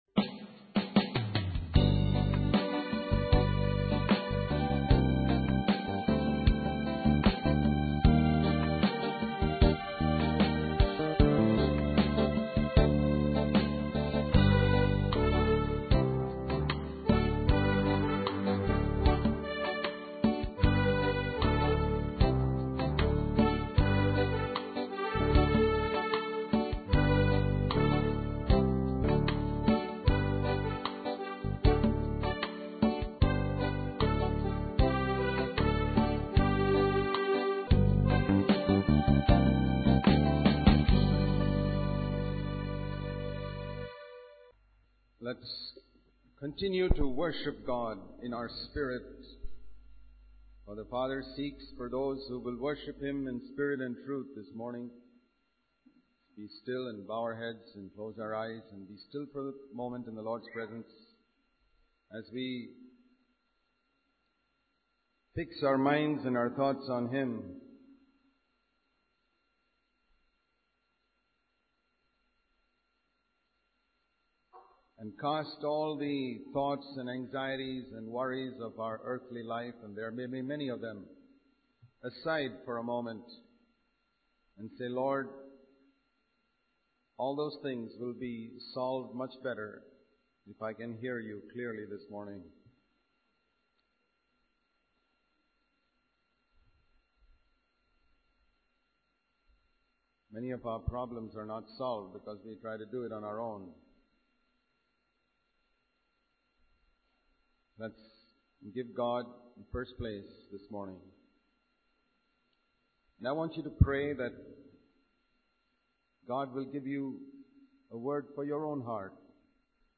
In this sermon, the speaker emphasizes the importance of self-reflection and humility in preaching the word of God. He encourages preachers to evaluate their sermons and strive to improve each time. The speaker highlights the ministry of Jesus, which was focused on setting people free and bringing them closer to God.